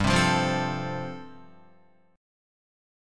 G9sus4 chord